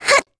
Talisha-Vox_Jump_kr.wav